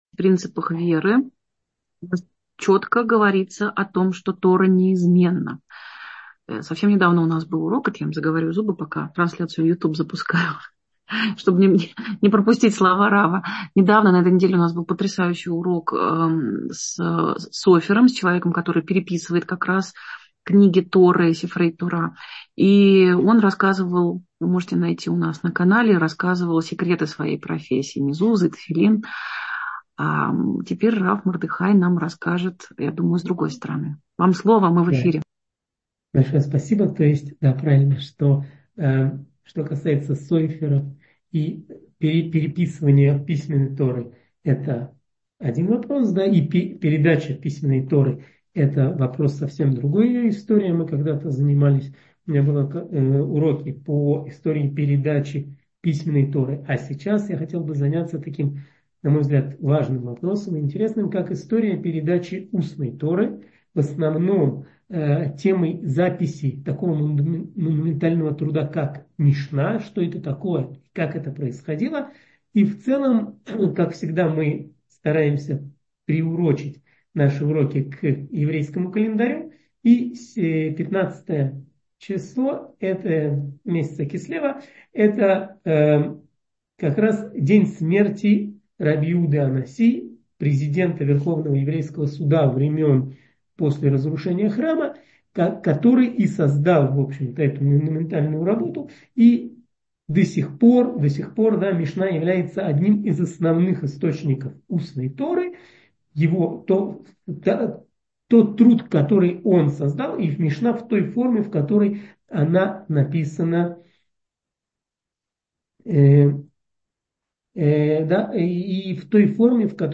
Аудиоуроки